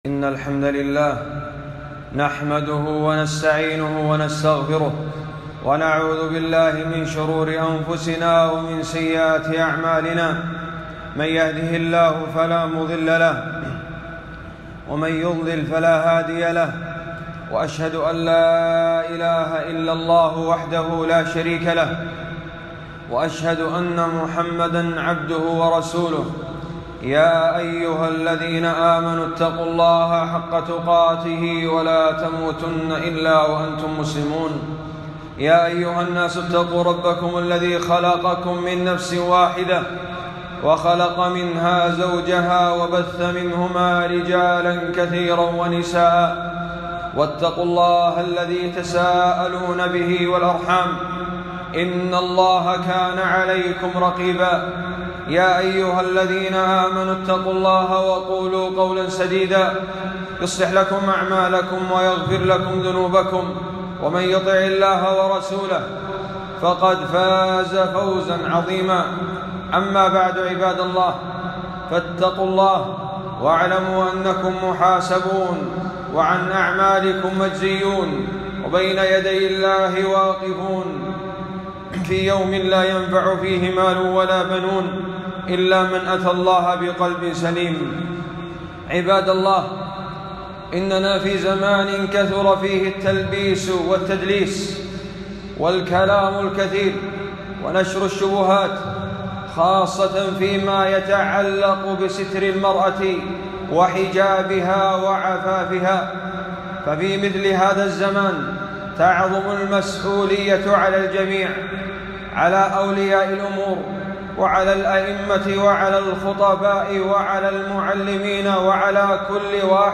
خطبة - سرعة استجابة الصحابيات لآيات الستر والعفاف